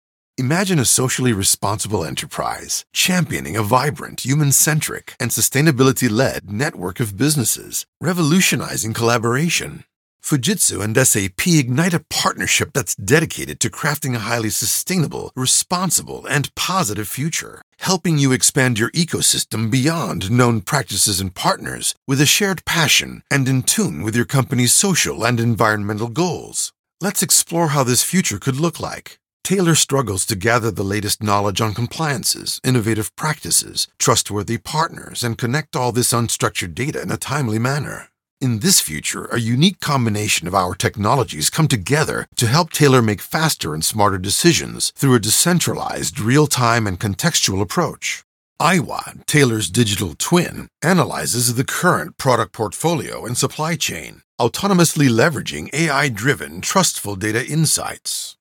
Male
English (North American)
Adult (30-50), Older Sound (50+)
All produced in a professional broadcast quality studio.
Television Spots